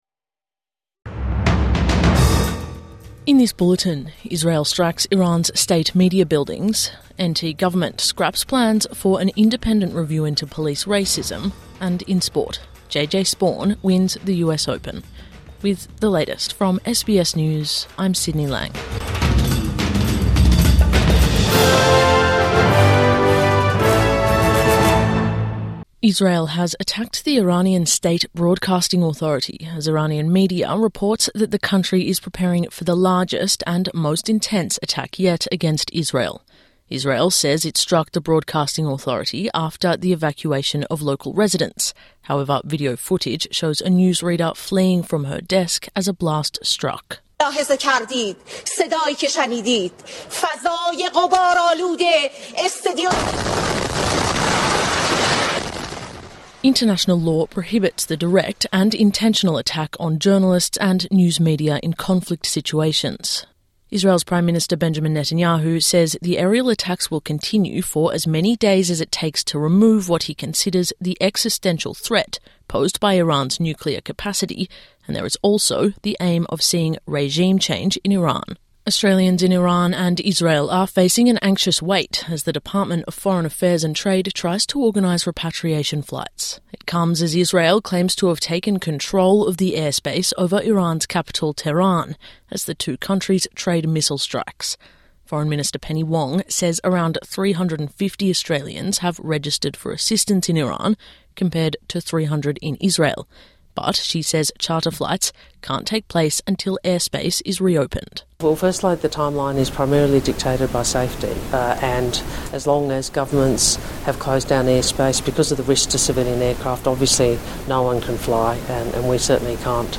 Israeli missile hits Iran state TV studios | Morning News Bulletin 17 June 2025